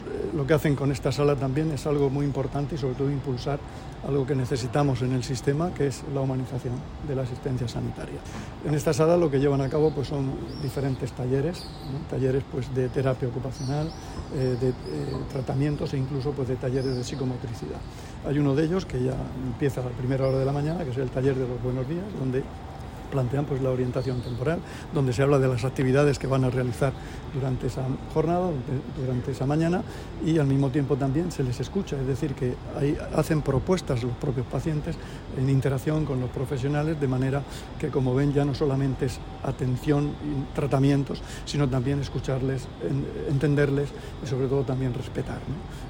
declaraciones del consejero de Salud, Juan José Pedreño, sobre los talleres que se realizan en la sala de terapia ocupacional del Servicio de Psiquiatría del hospital Reina Sofía.